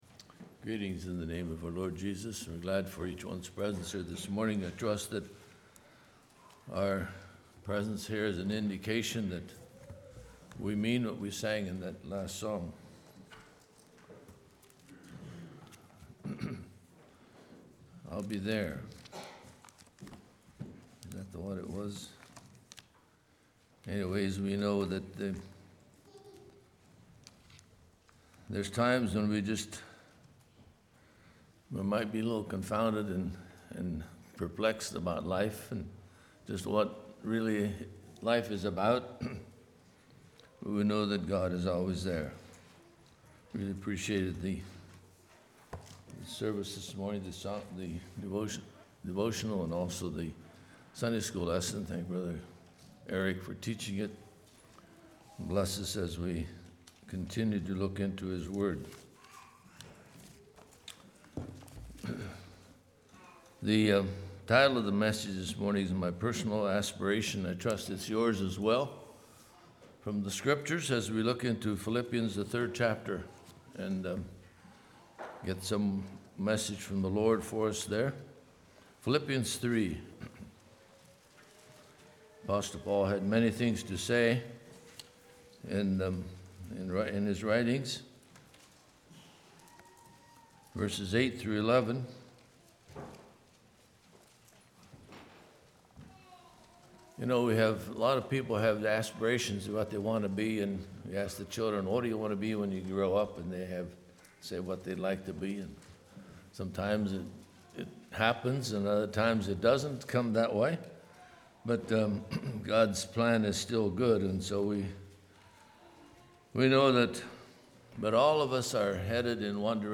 Congregation: Mount Joy
Sermon